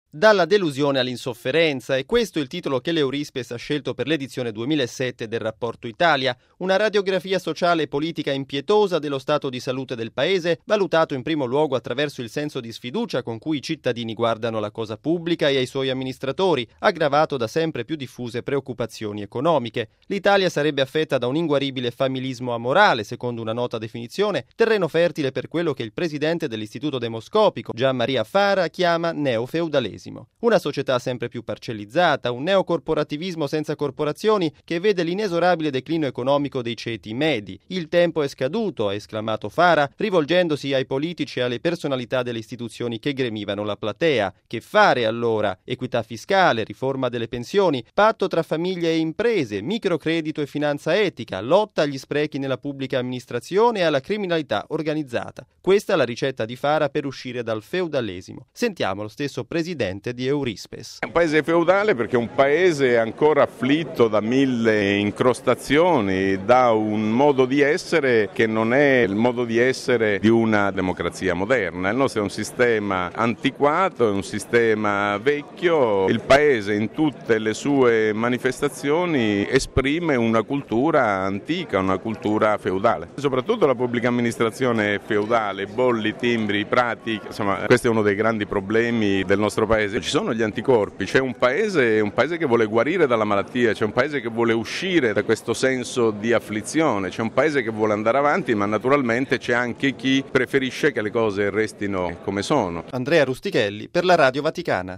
È la cupa fotografia che emerge dal XIX Rapporto Italia dell’Eurispes, presentato stamani a Roma. Il servizio